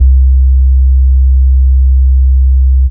Menacing Bass 1 (JW3).wav